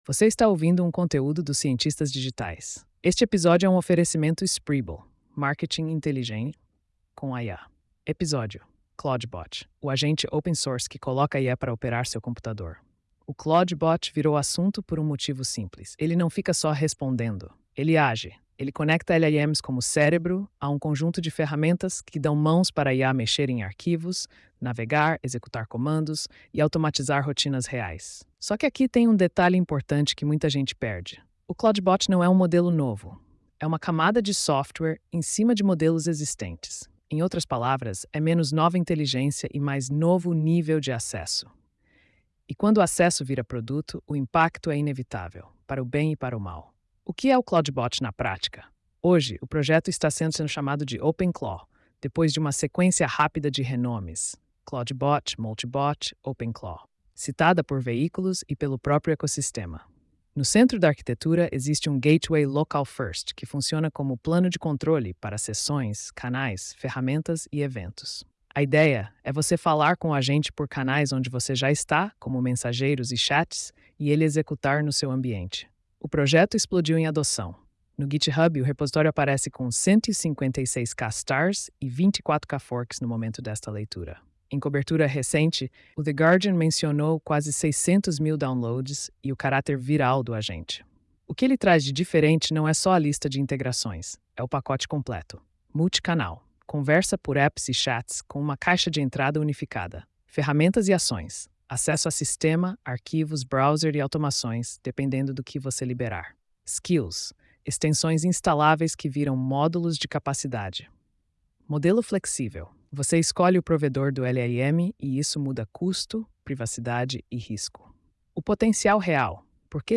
post-4649-tts.mp3